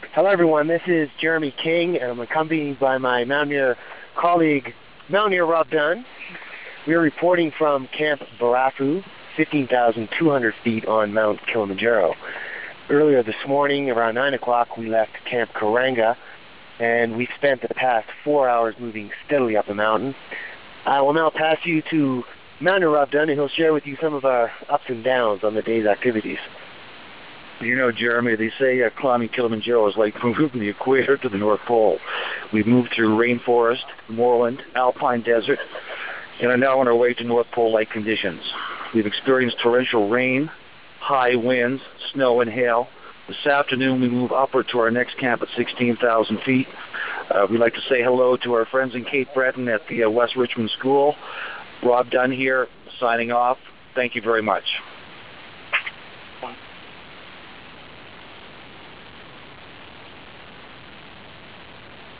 reporting from the mountain